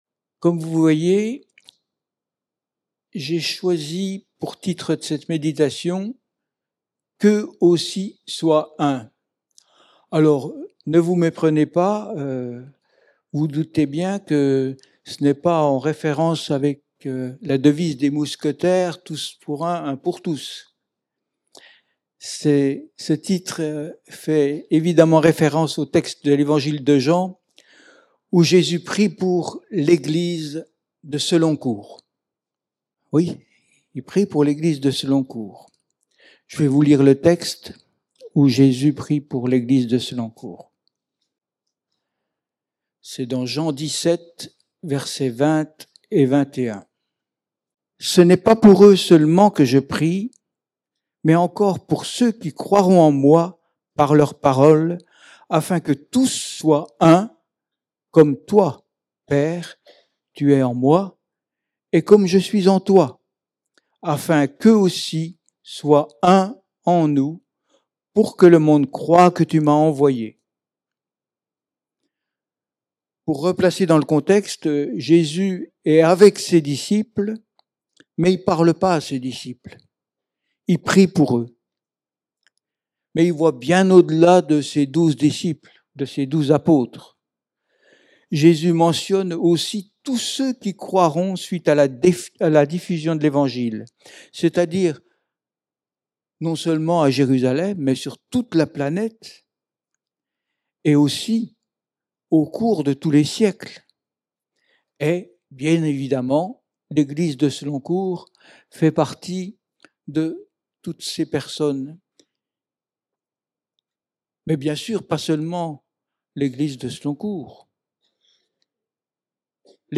Culte hebdomadaire